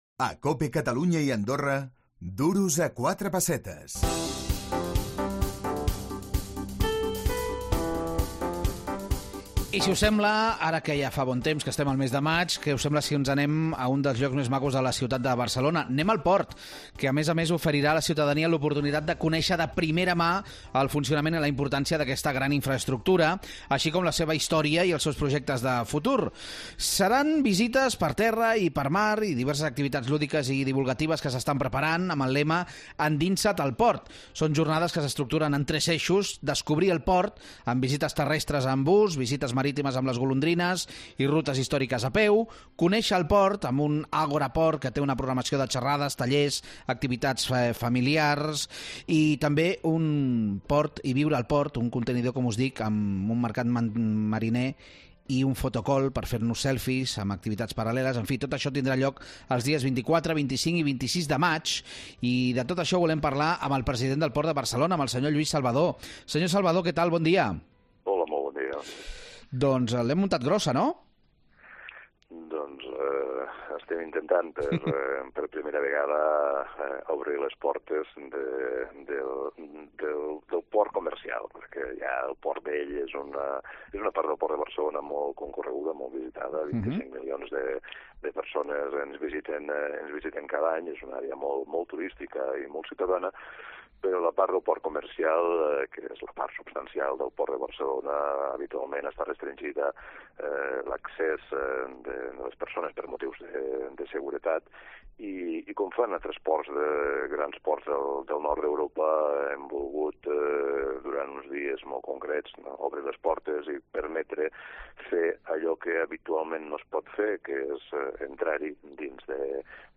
AUDIO: Ens ho explica el President del Port de Barcelona, Lluís Salvador